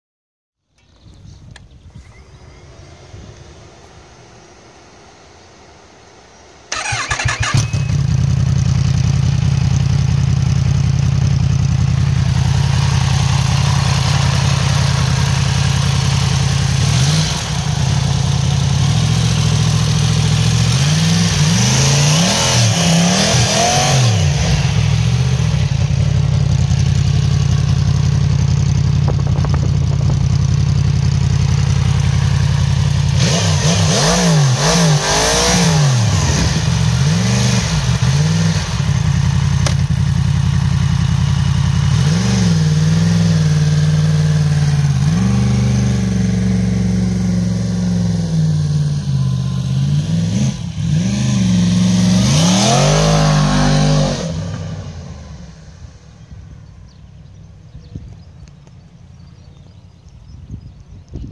Le grondement amical qui sort du moteur de la dernière-née de Yamaha a quelque chose d’à la fois intoxicant et de très familier. Pas de rage inutile là-dedans, mais une émotion, un plaisir immédiat.
Voici deux petits échantillons, capturés près de l’hôtel.